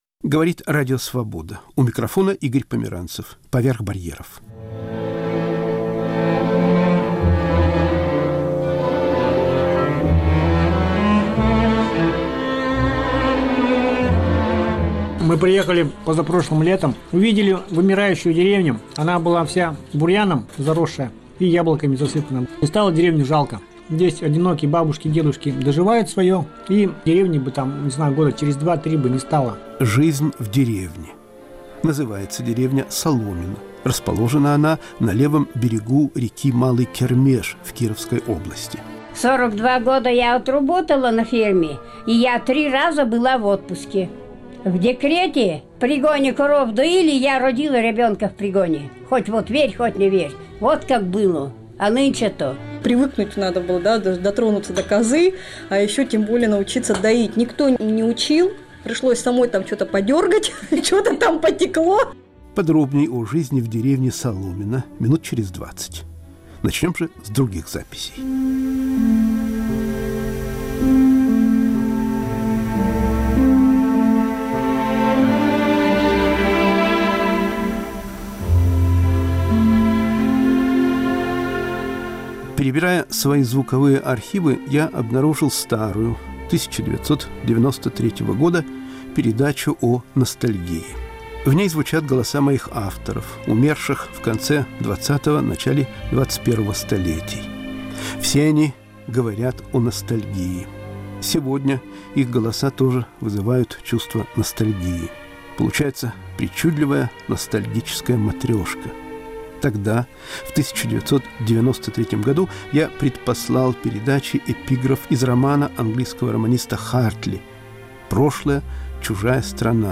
Передача из архива «Поверх барьеров» (1993). Звучат голоса Александра Пятигорского, Булата Окуджава и др.** «Жизнь в деревне».